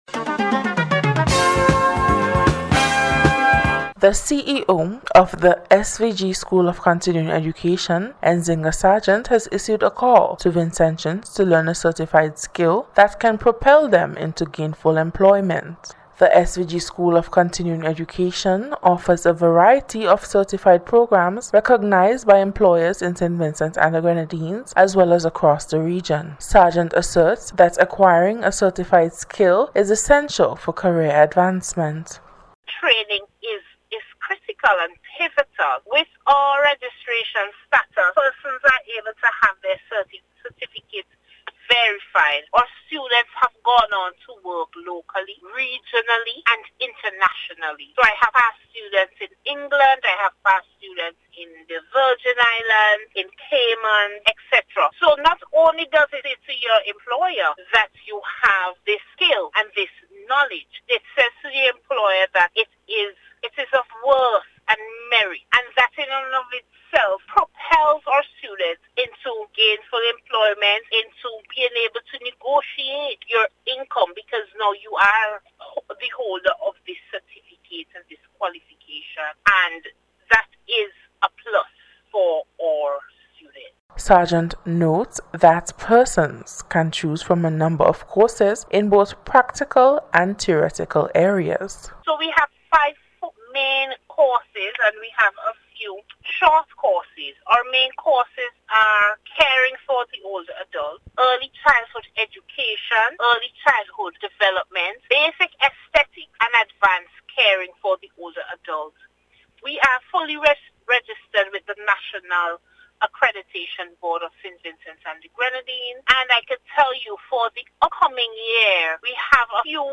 NBC’s Special Report- Wednesday 29th January,2025